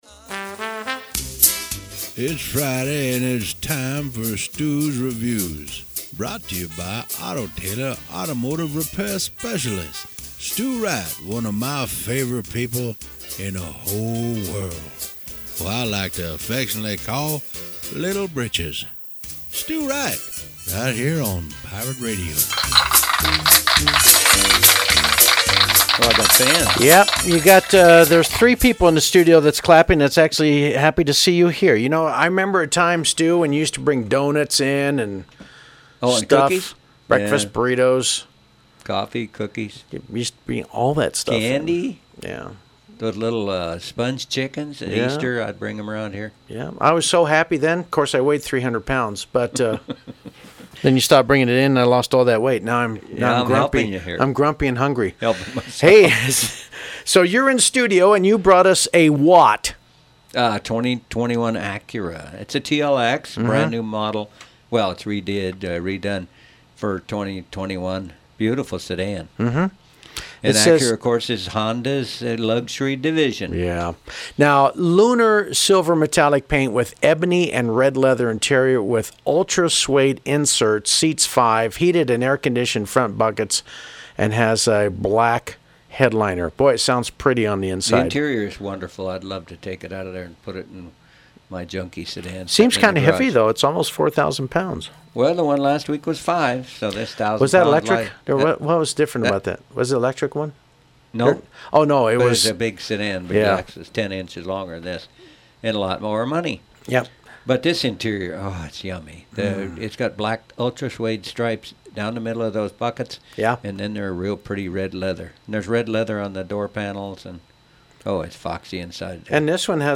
DJ
over at Pirate Radio studios